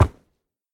Sound / Minecraft / mob / horse / wood5.ogg
wood5.ogg